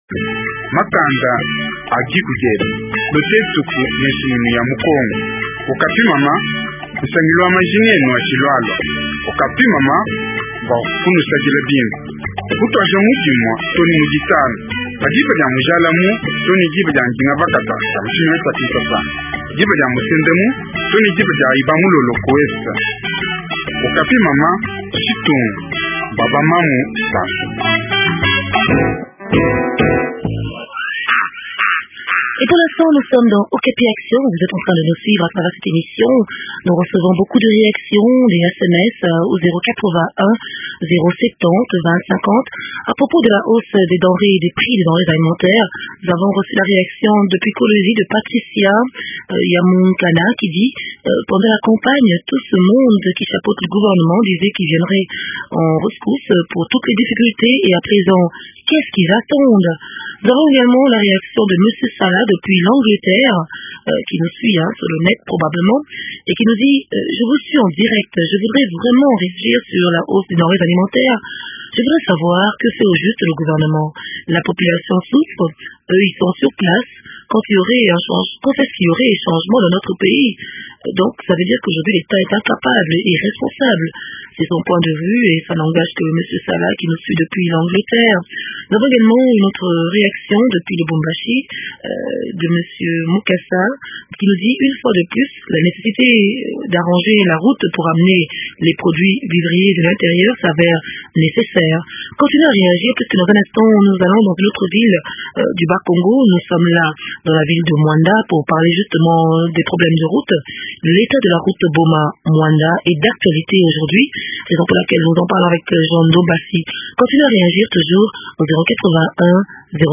reçoivent Dr Bavuidi, Ministre Provincial des infrastructures.